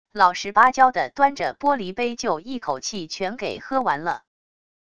老实巴交地端着玻璃杯就一口气全给喝完了wav音频生成系统WAV Audio Player